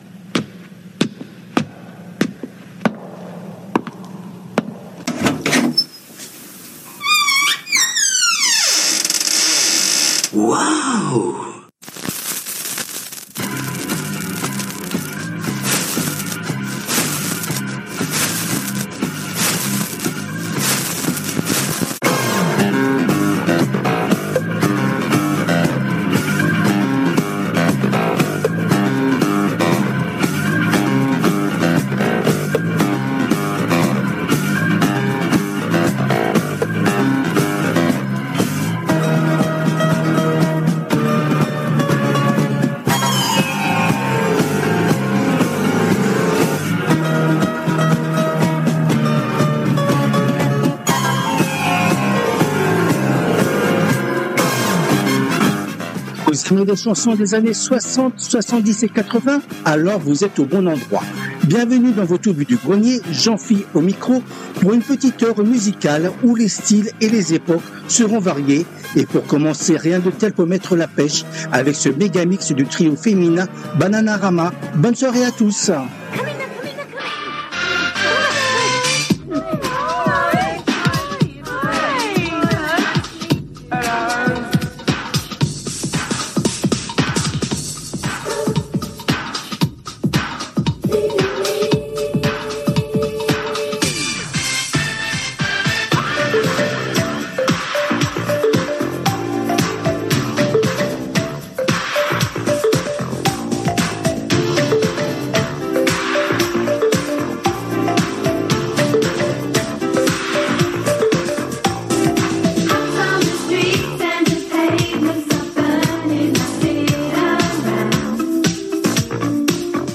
Les Tubes connus ou oubliés des 60's, 70's et 80's